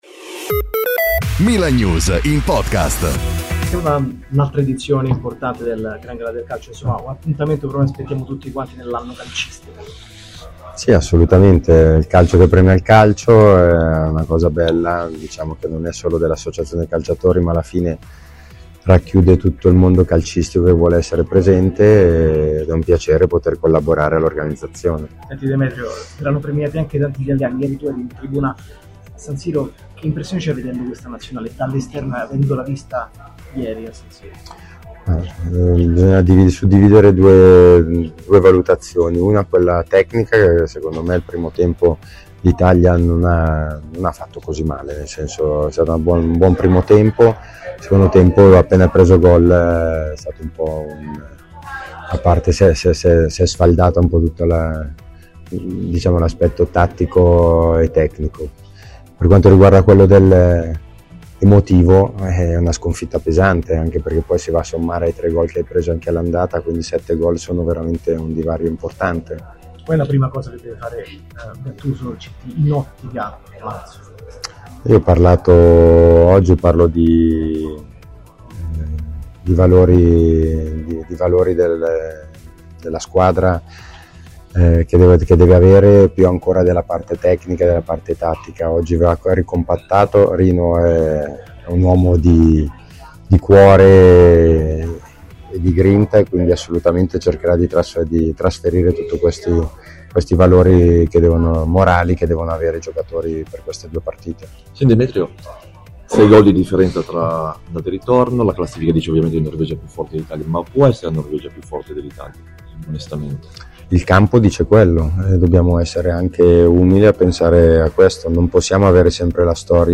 Demetrio Albertini, ex giocatore rossonero, a margine della presentazione del Galà del Calcio AIC, ha parlato così del Milan, del derby, di Modric e di Leao.